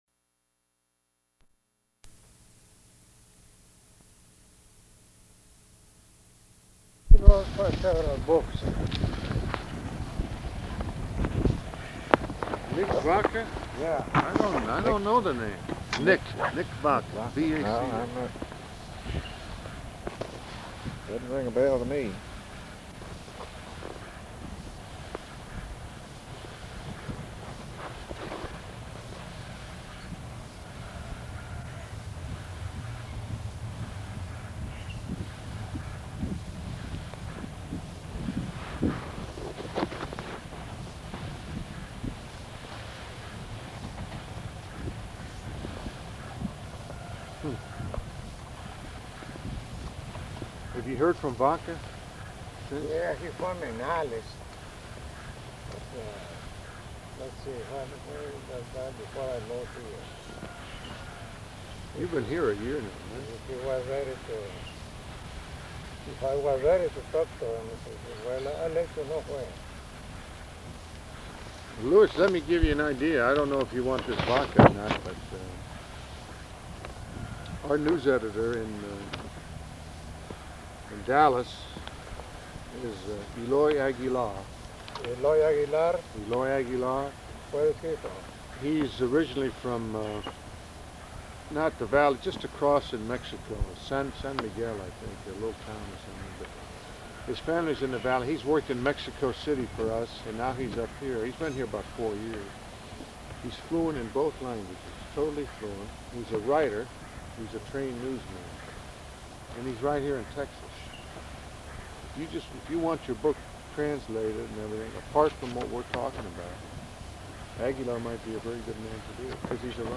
Outdoors; a lot of it is difficult to hear
Specific Item Type Interview Subject Congressional Elections Texas